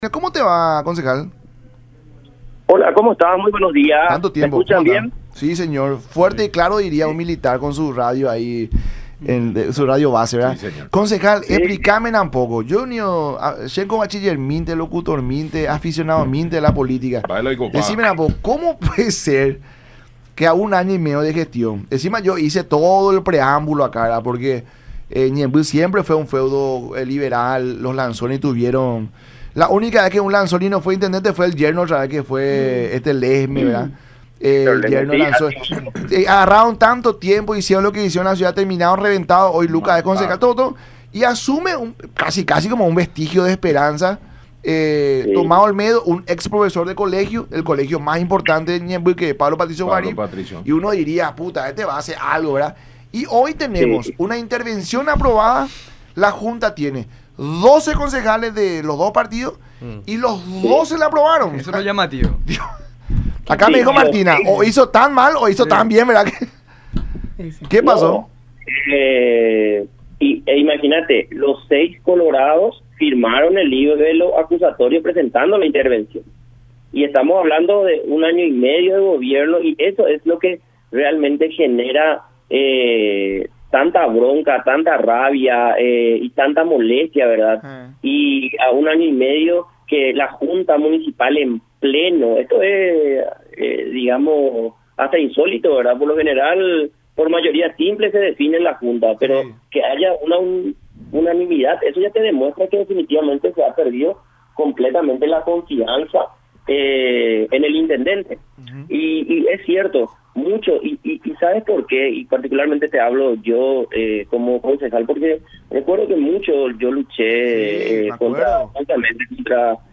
“Votaron a favor de la intervención los 12 concejales. Los 6 colorados y los 6 liberales. Si por unanimidad se aprobó, tenés la pauta de que definitivamente se ha perdido la confianza en el intendente”, dijo Marcelo Martínez, concejal ñembyense por la ANR, en diálogo con La Mañana De Unión por Unión TV y radio La Unión.